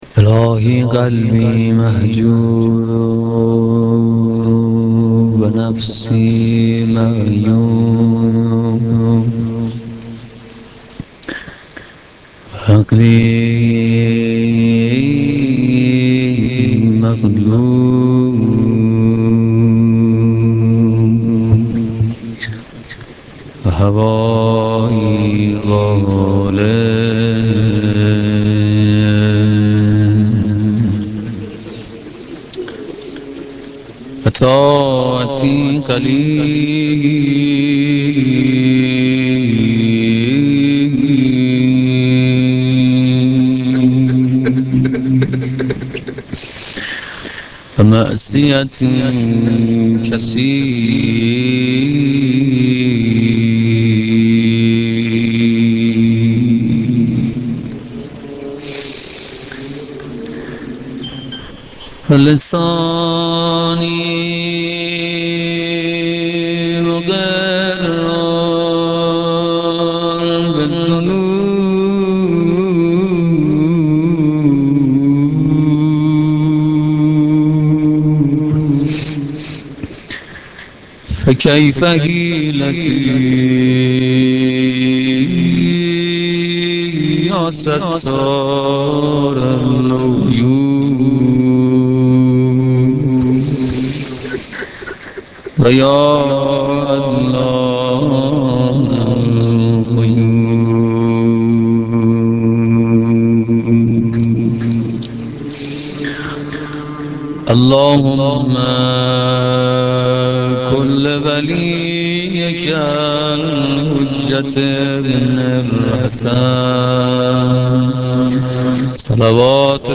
مداحی روضه شب سوم